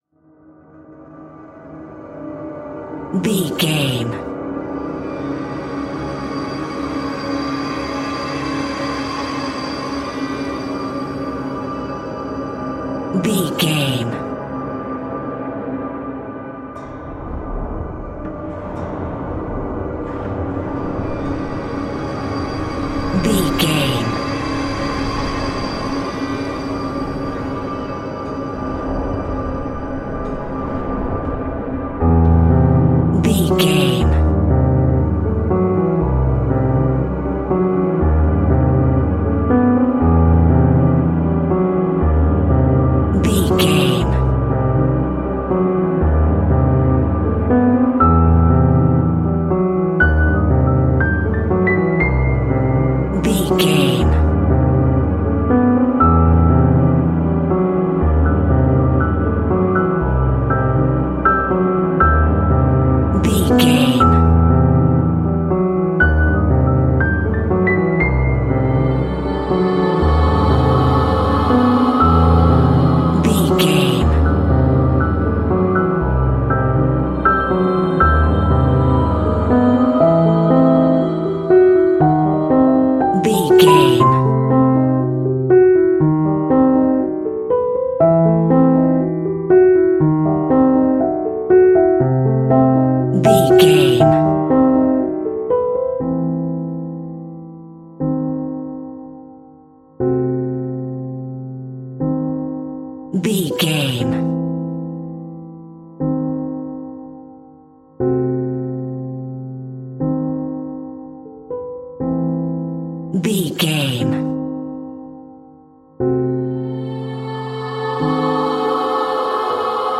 In-crescendo
Thriller
Aeolian/Minor
scary
ominous
dark
suspense
eerie
piano
synthesiser
horror
ambience
pads
eletronic